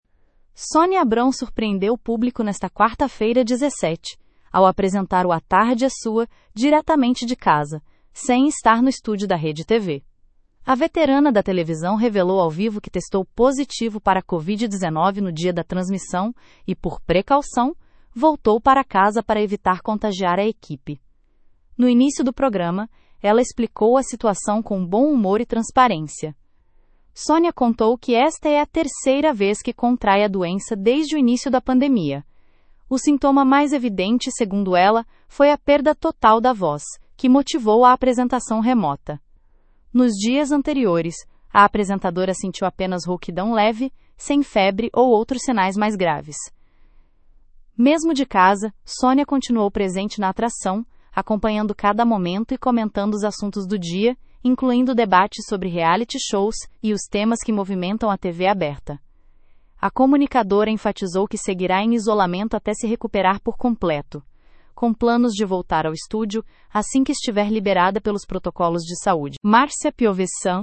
Sônia Abrão surpreendeu o público nesta quarta-feira (17) ao apresentar o A Tarde é Sua diretamente de casa, sem estar no estúdio da RedeTV!.
Nos dias anteriores, a apresentadora sentiu apenas rouquidão leve, sem febre ou outros sinais mais graves.